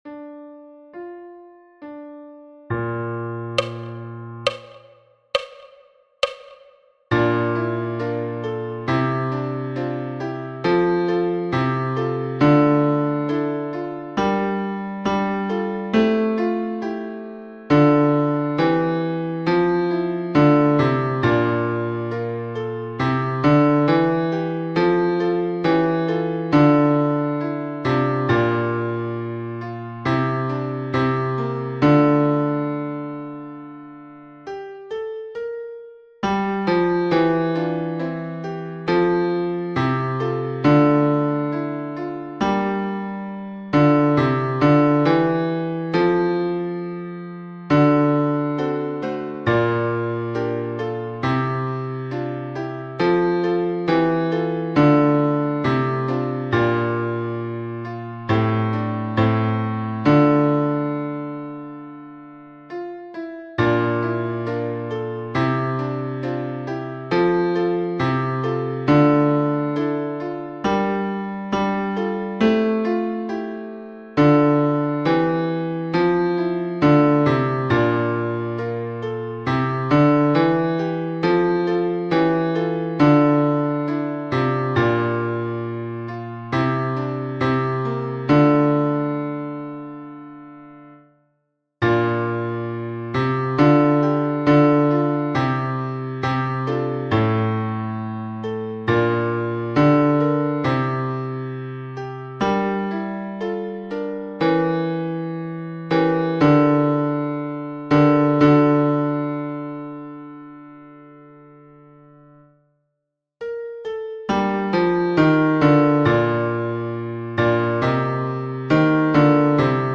night-bass.mp3